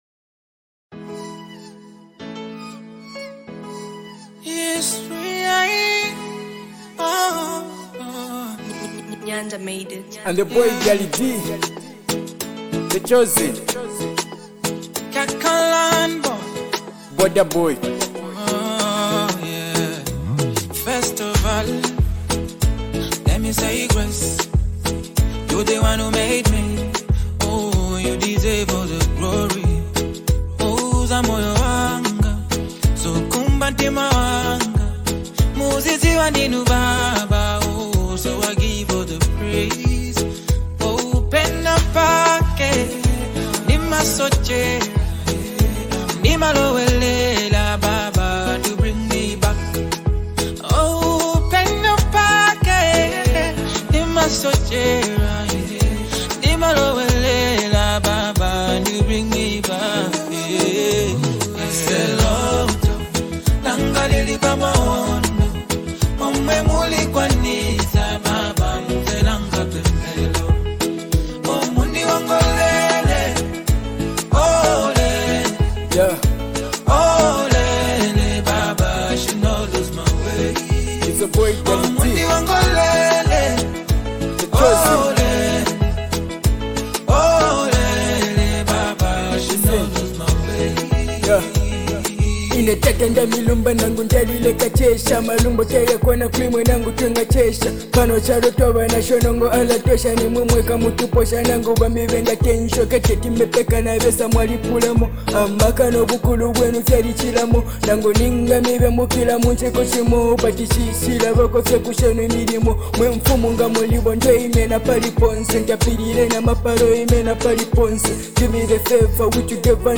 captures the vibrant energy of Zambia‘s modern sound
Genre: Afrobeat